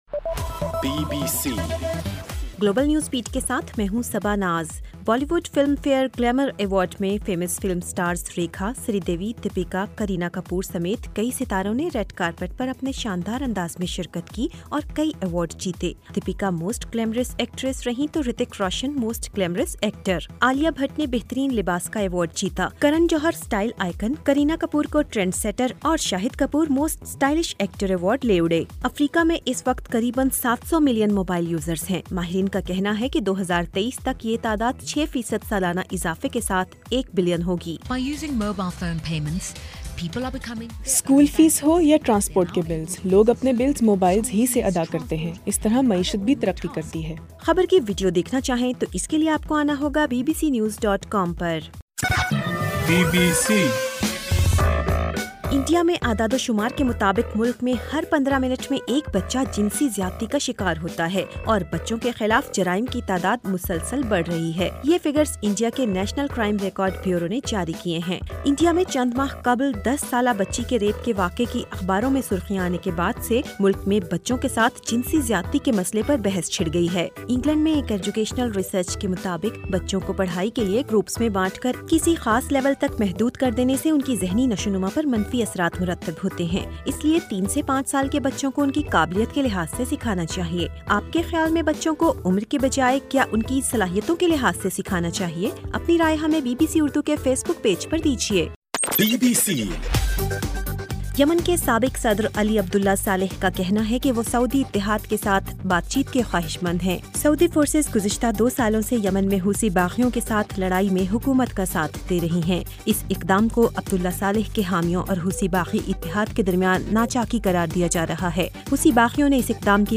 گلوبل نیوز بیٹ بُلیٹن اُردو زبان میں رات 8 بجے سے صبح 1 بجے تک ہر گھنٹےکے بعد اپنا اور آواز ایف ایم ریڈیو سٹیشن کے علاوہ ٹوئٹر، فیس بُک اور آڈیو بوم پر ضرور سنیے۔